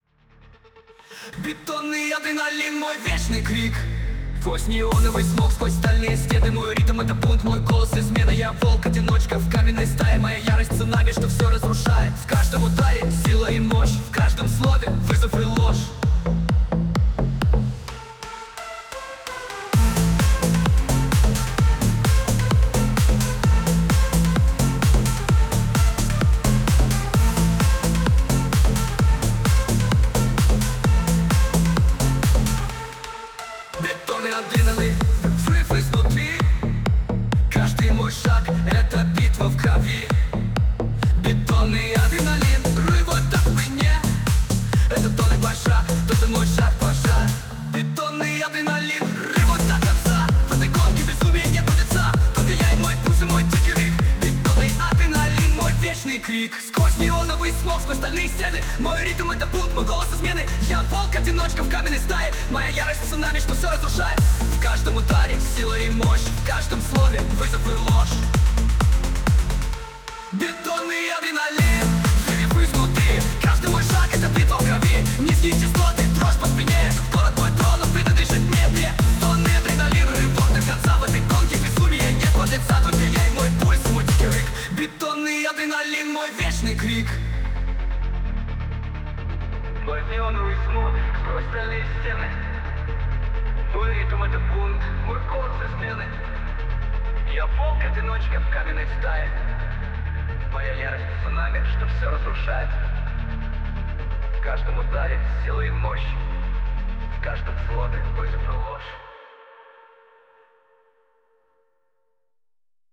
Жанр: Hip Hop